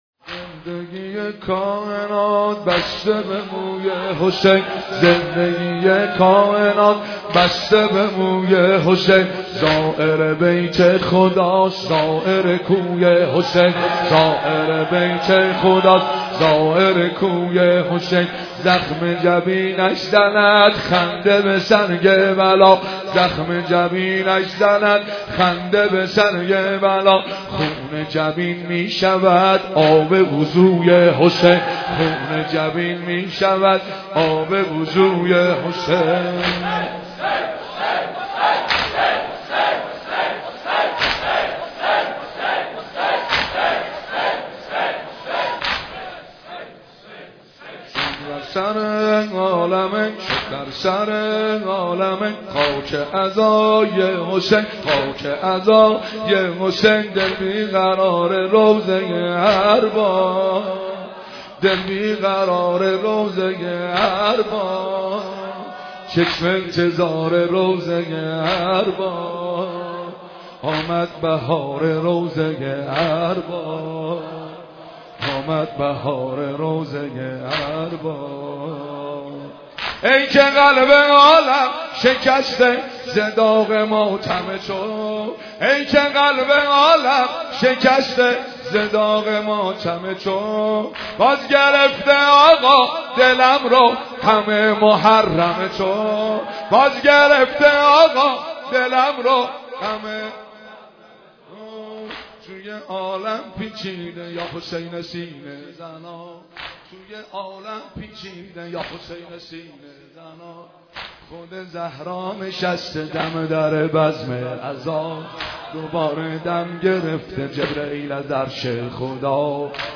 زندگی کائنات بسته به موی حسین(ع) است/واحد زیبا